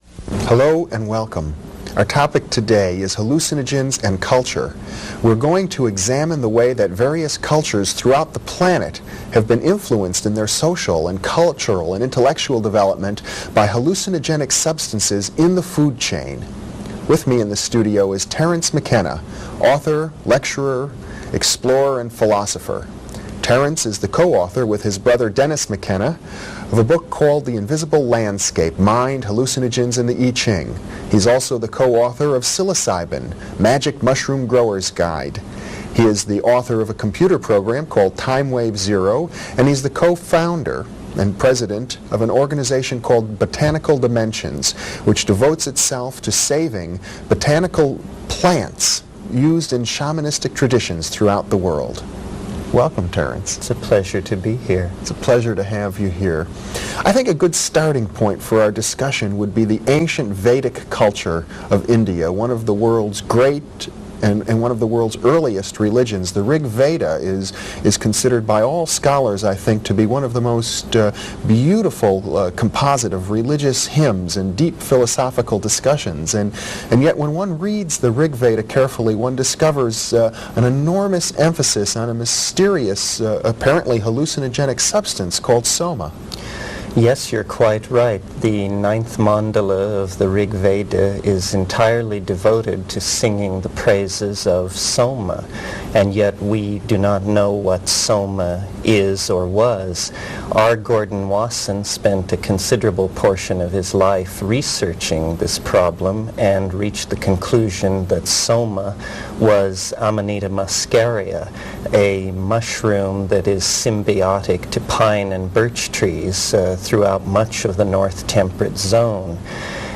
Terence McKenna： Hallucinogens And Culture (Full Lecture And Interview) [9AaZy5pVdpA].opus